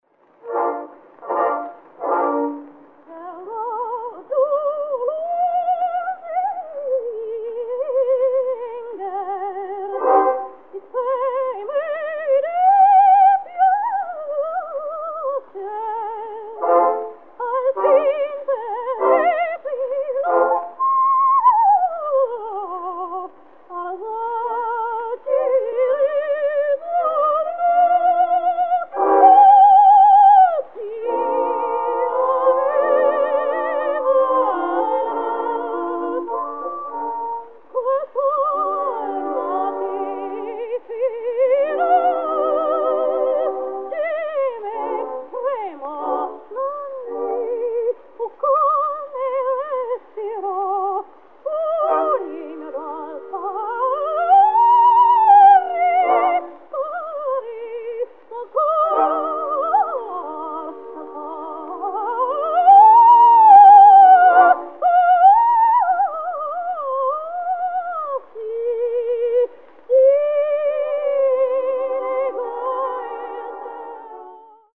This CD is a collection of 18 restored songs.
was an Australian dramatic soprano